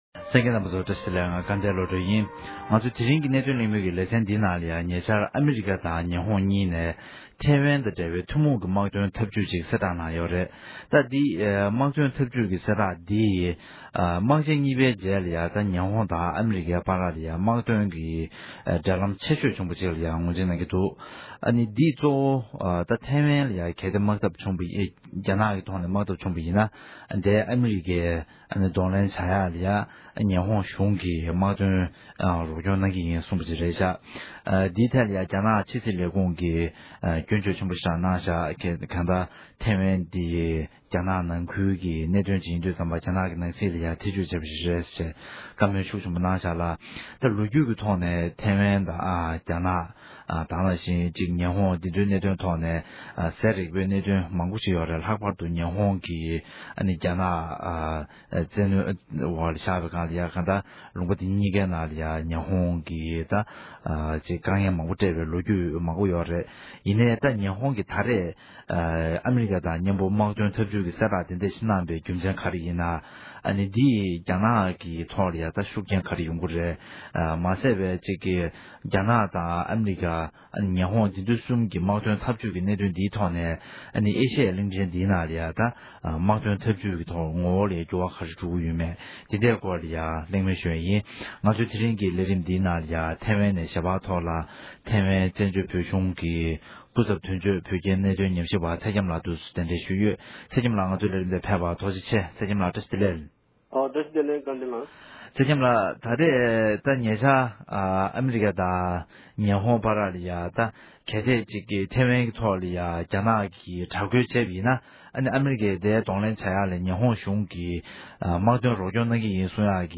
The Call-In Show